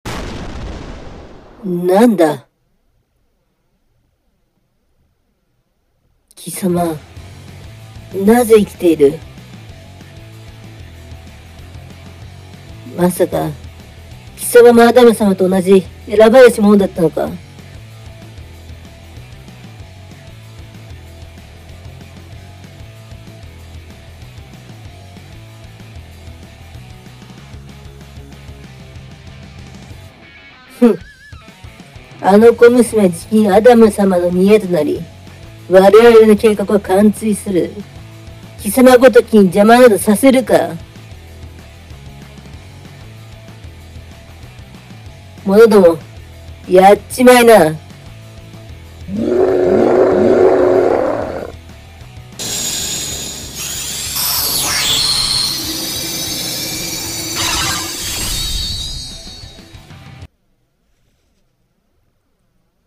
【2人声劇】特撮ヒーロー/ヒロイン 〜中ボス編〜